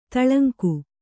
♪ taḷaŋku